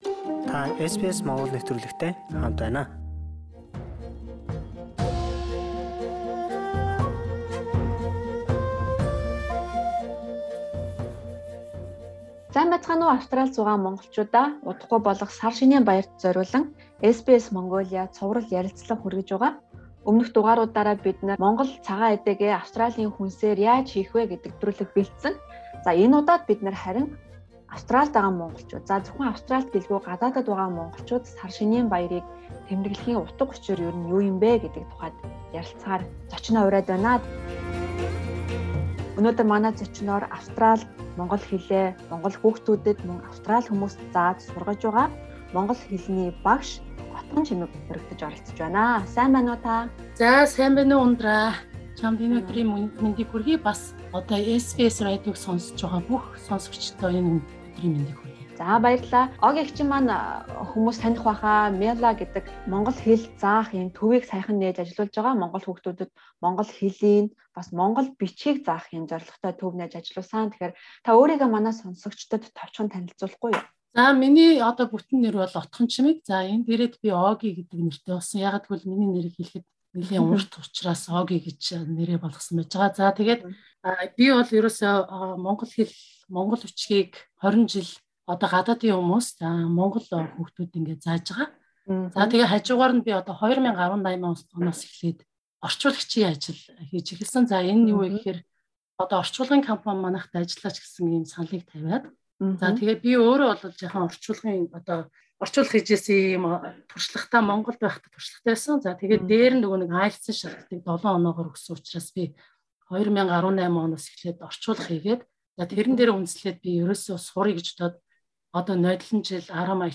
ярилцлаа.